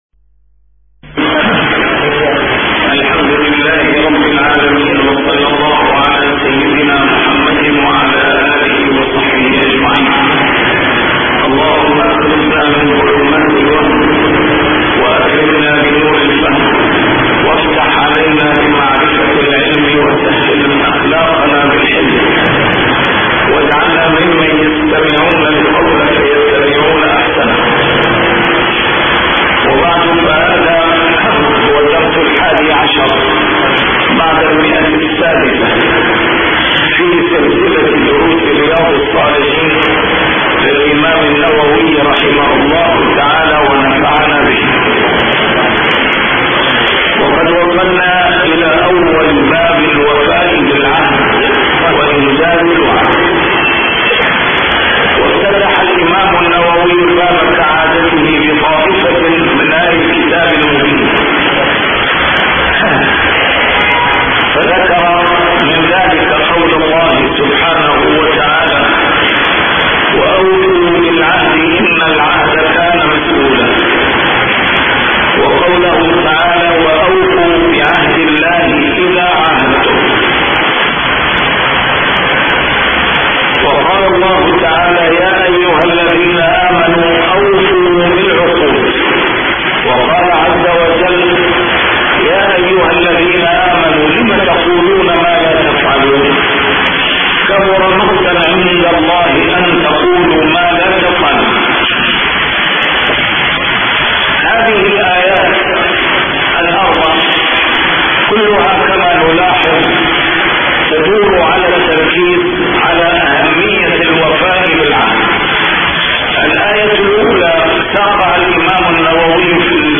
A MARTYR SCHOLAR: IMAM MUHAMMAD SAEED RAMADAN AL-BOUTI - الدروس العلمية - شرح كتاب رياض الصالحين - 611- شرح رياض الصالحين: الوفاء بالعهد وإنجاز الوعد